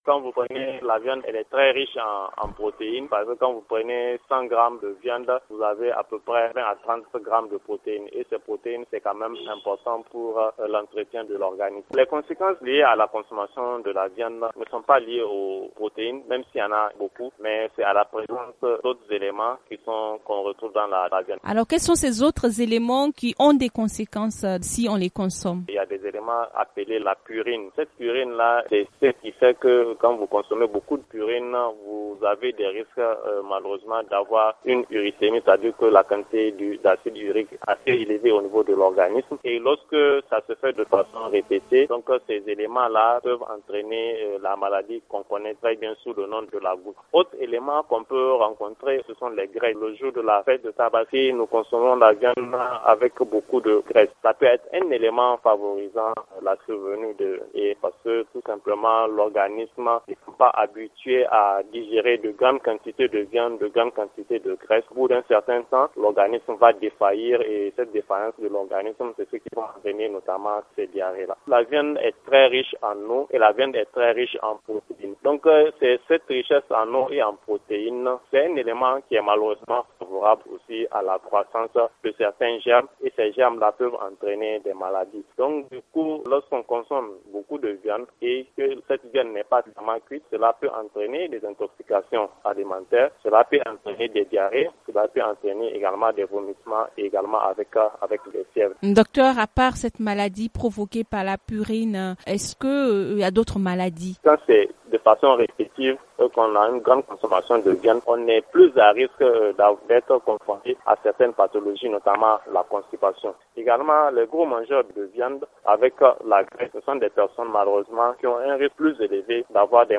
FR Magazine en Français https